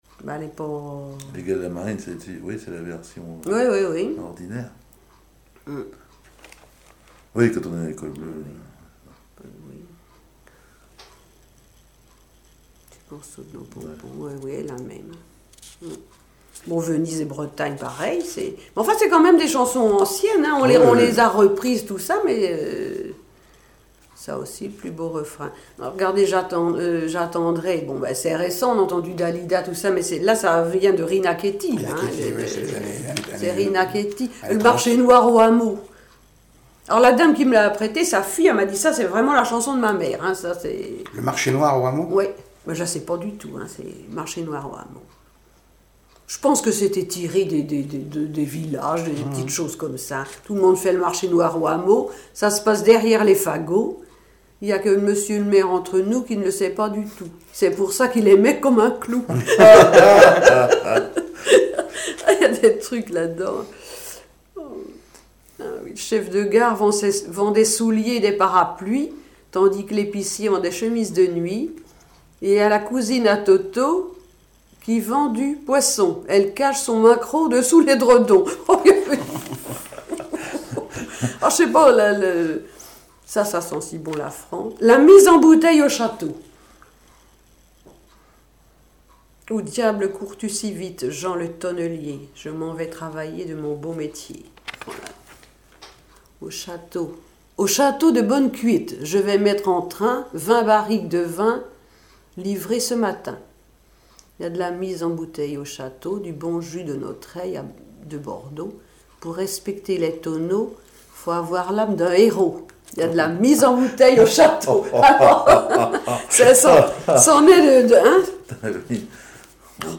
Localisation Saint-Pierre-en-Port
Catégorie Témoignage